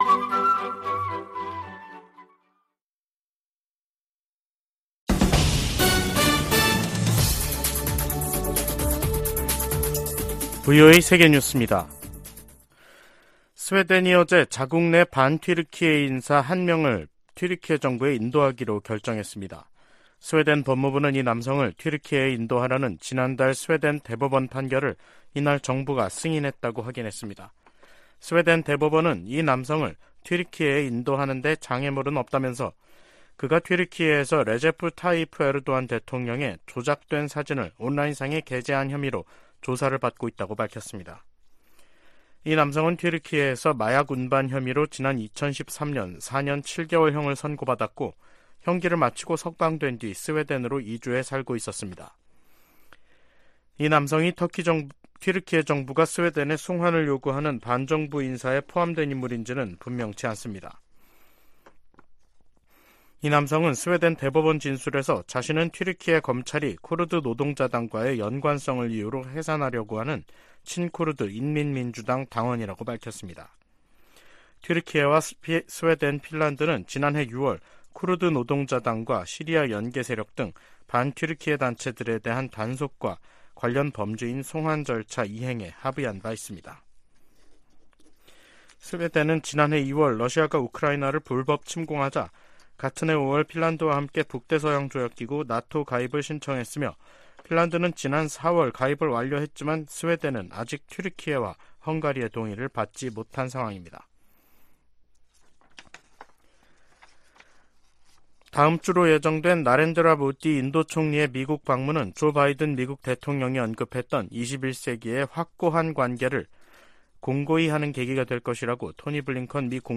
VOA 한국어 간판 뉴스 프로그램 '뉴스 투데이', 2023년 6월 13일 2부 방송입니다. 미국의 북핵 수석 대표는 워싱턴에서 한국의 북핵 수석대표와 회담한 후 북한의 추가 도발에 독자제재로 대응할 것이라는 입장을 밝혔습니다. 북한은 군사정찰위성 추가 발사 의지를 밝히면서도 발사 시한을 미리 공개하지 않겠다는 입장을 보이고 있습니다.